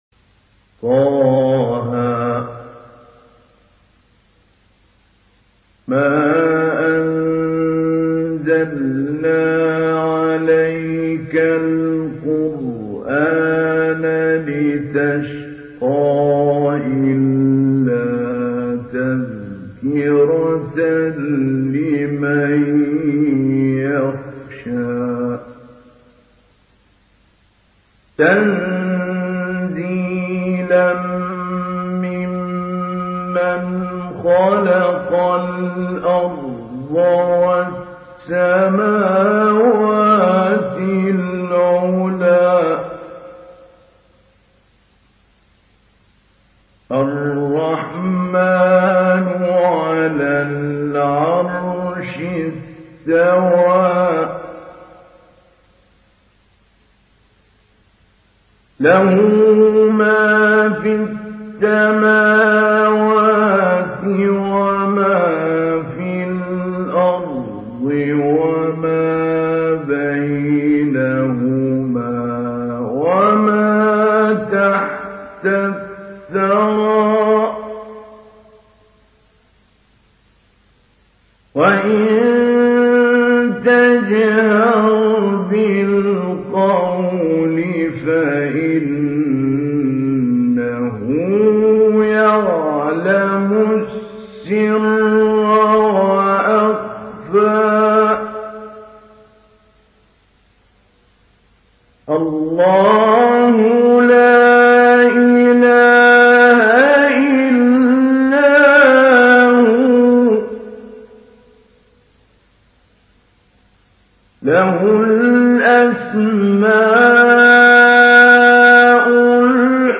Sourate Taha Télécharger mp3 Mahmoud Ali Albanna Mujawwad Riwayat Hafs an Assim, Téléchargez le Coran et écoutez les liens directs complets mp3
Télécharger Sourate Taha Mahmoud Ali Albanna Mujawwad